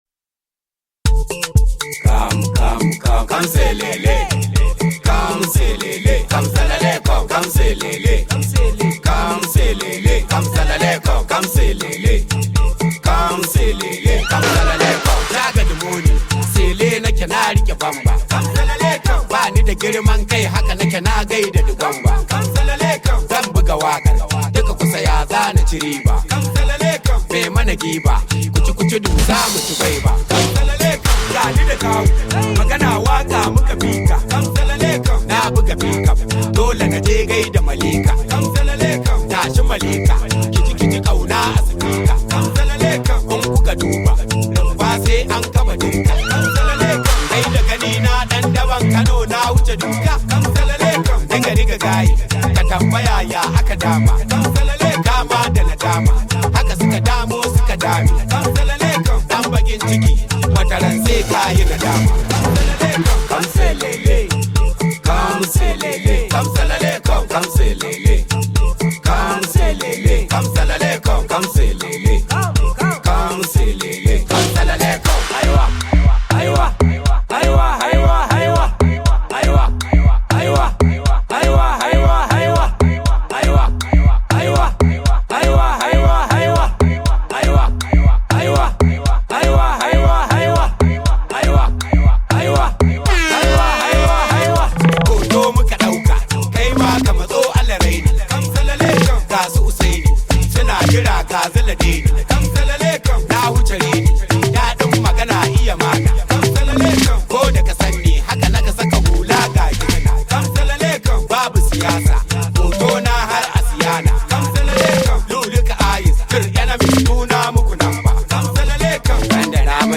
top rated Nigerian Hausa Music artist
high vibe hausa song